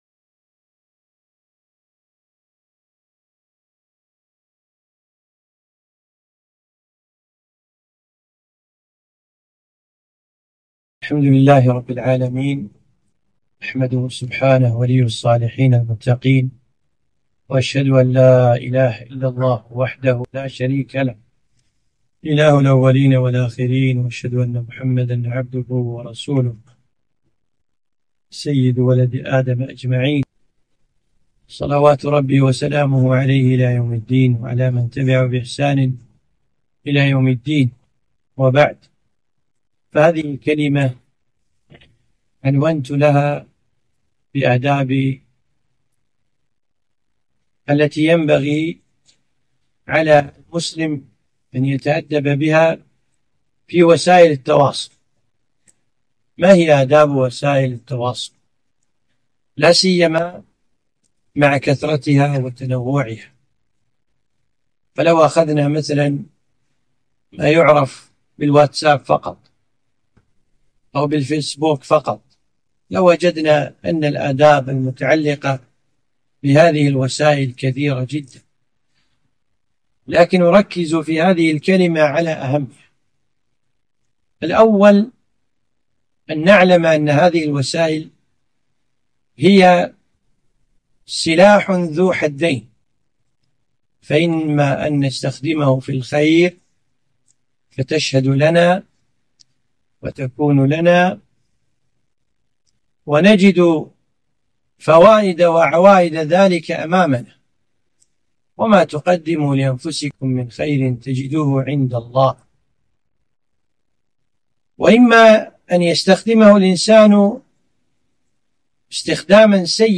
كلمة - آداب وسائل التواصل